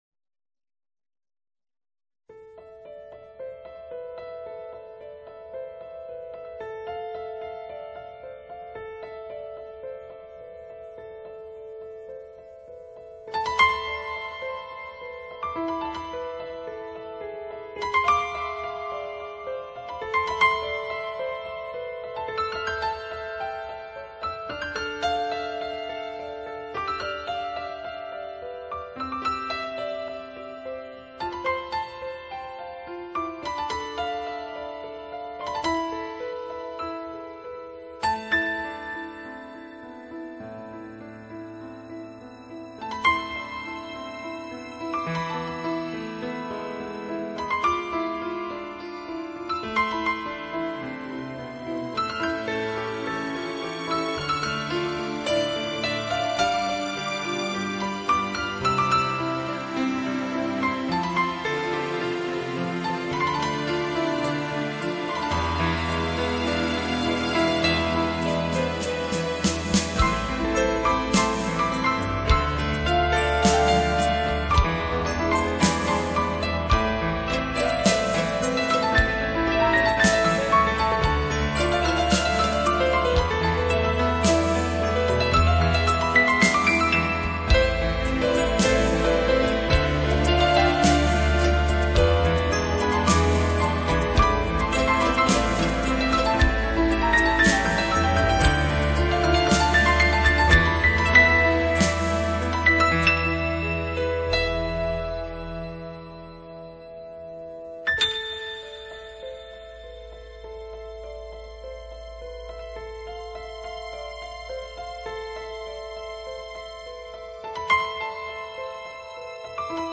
类型：New Age
轻柔的吉它、优雅的长笛佐以婉约的钢琴，